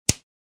Download Free Cooking Sound Effects | Gfx Sounds
Chocolate-bar-piece-snap-crack.mp3